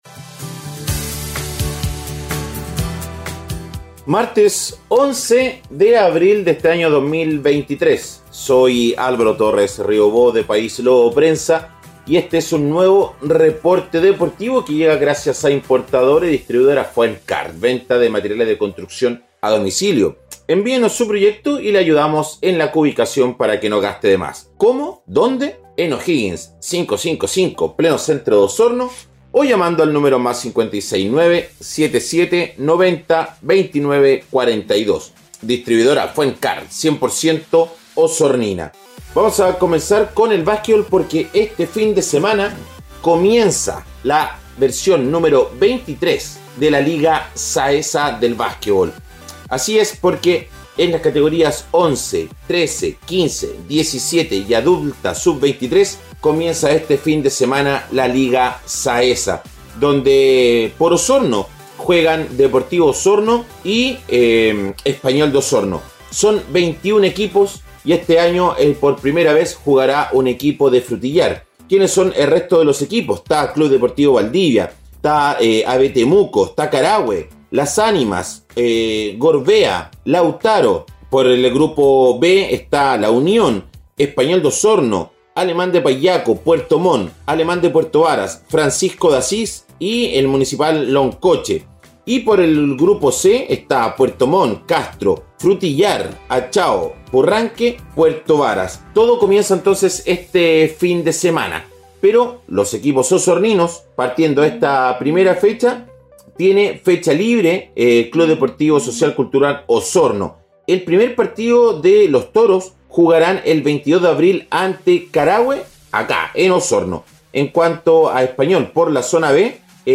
Reporte Deportivo 🎙 Podcast 11 de abril de 2023